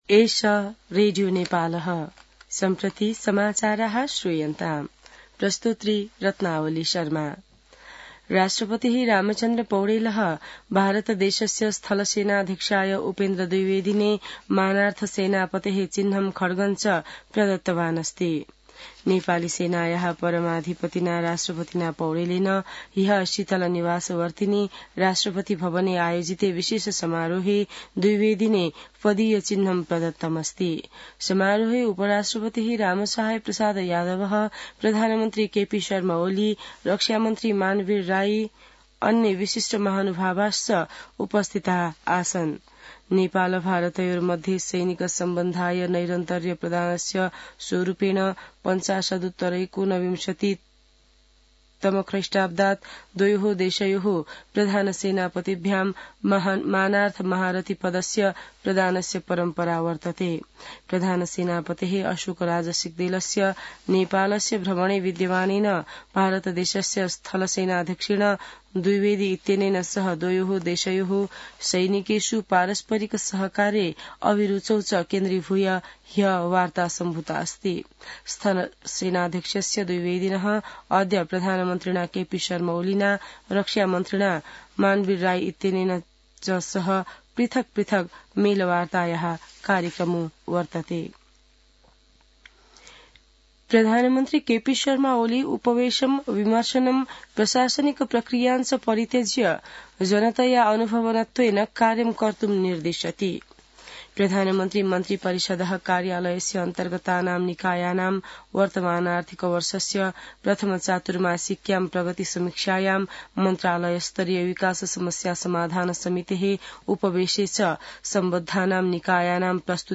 संस्कृत समाचार : ८ मंसिर , २०८१